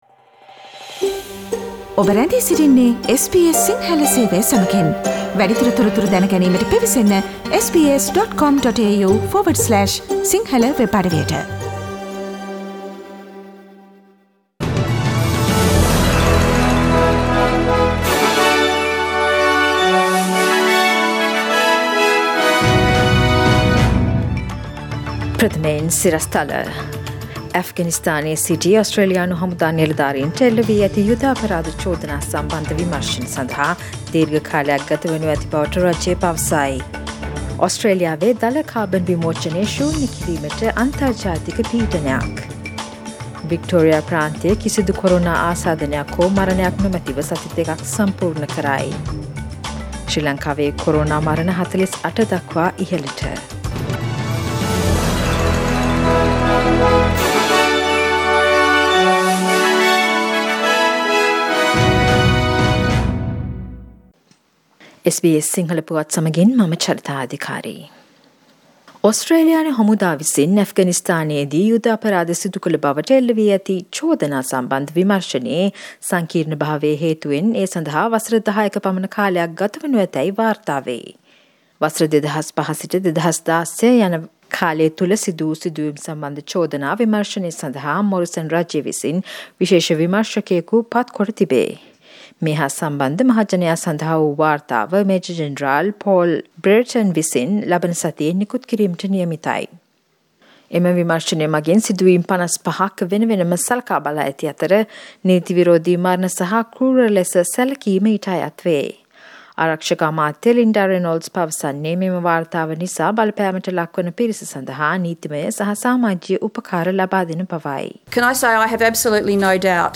Daily News bulletin of SBS Sinhala Service: Friday 13 November 2020
Today’s news bulletin of SBS Sinhala radio – friday 13 November 2020.